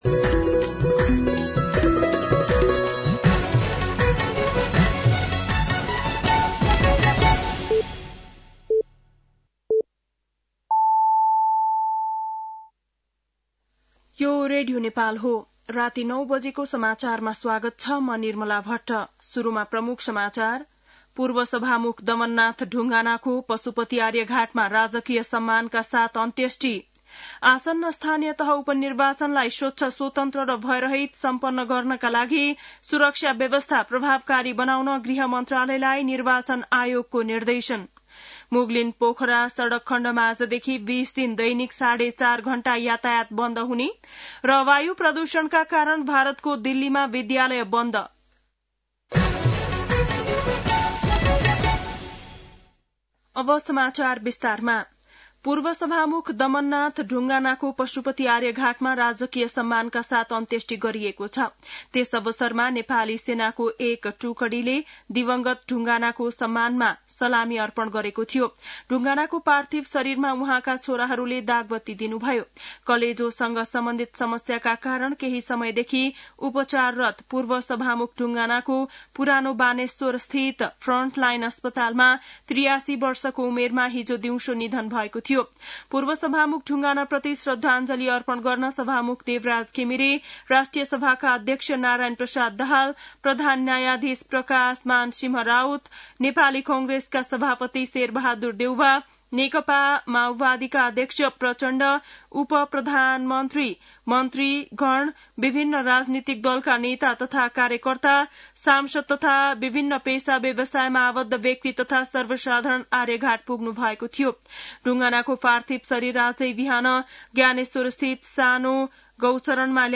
बेलुकी ९ बजेको नेपाली समाचार : ४ मंसिर , २०८१
9-PM-Nepali-NEWS-8-03.mp3